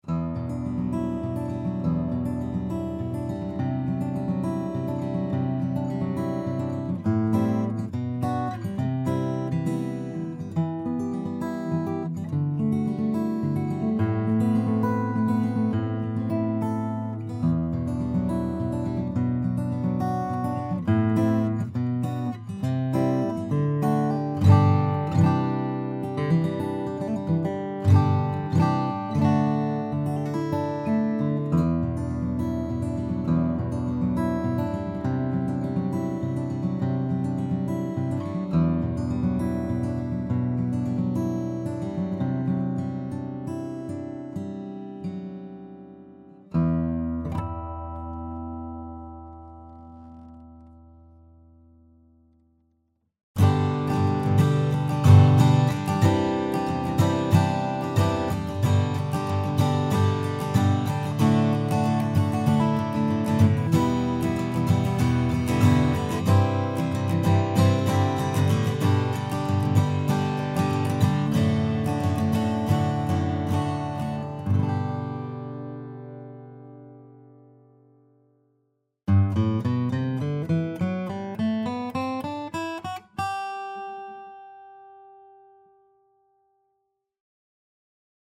Red Cedar top, Wenge back and sides, and African Blackwood fretboard and appointments. This guitar is a knockout in terms of playability, quality of workmanship, and TONE. The sound of this guitar is extremely well rounded and defined.